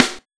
SNARE53.wav